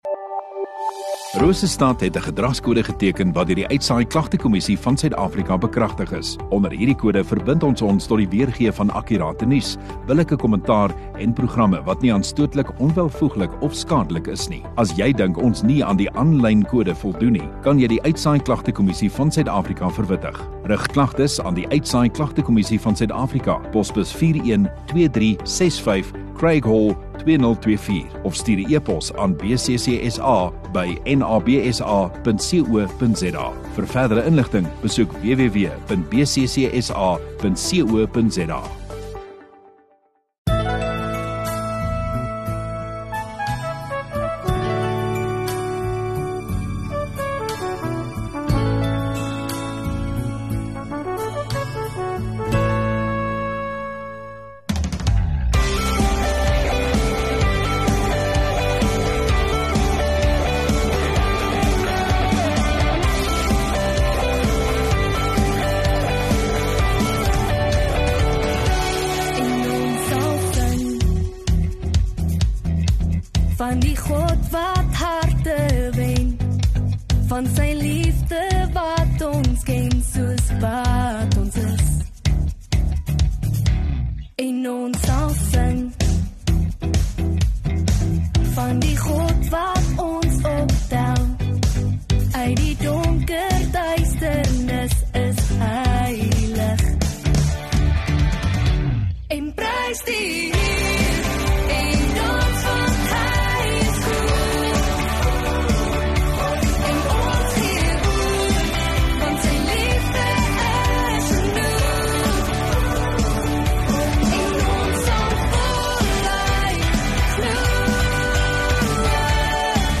30 Nov Saterdag Oggenddiens